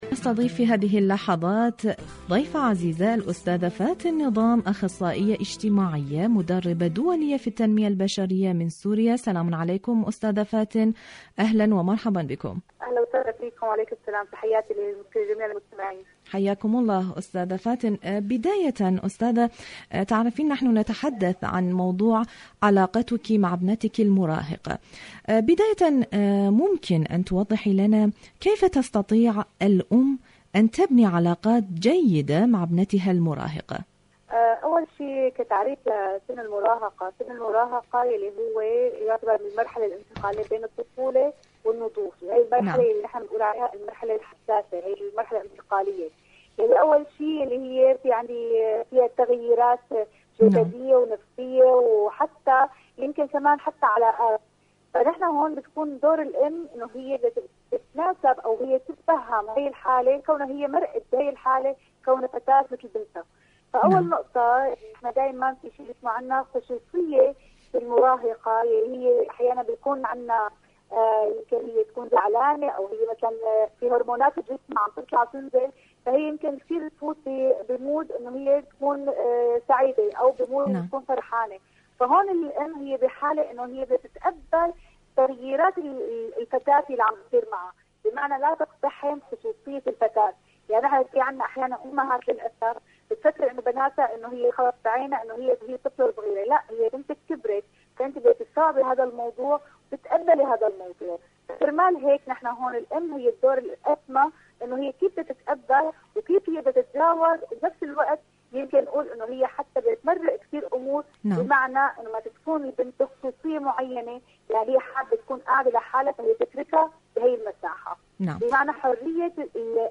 علاقتك مع ابنتك المراهقة.. مقابلة